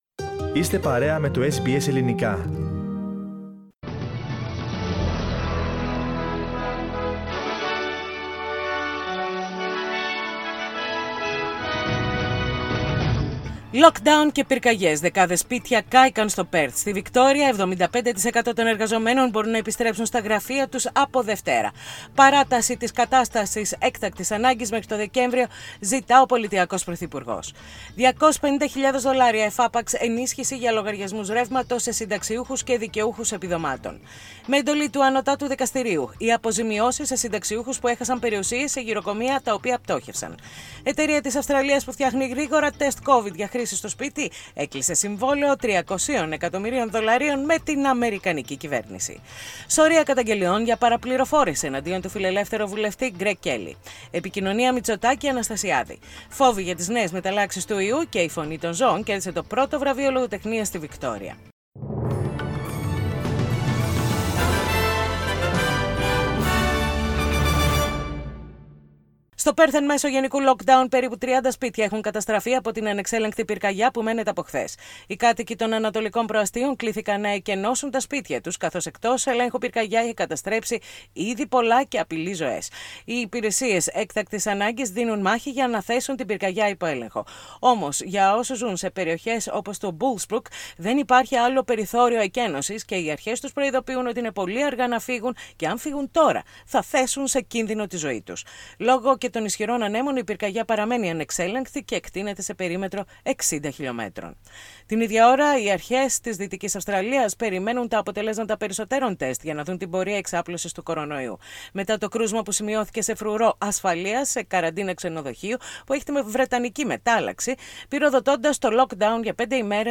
Δελτίο ειδήσεων - Τρίτη 2.2.21
Οι κυριότερες ειδήσεις της ημέρας απο τό Ελληνικό πρόγραμμα της ραδιοφωνίας SBS.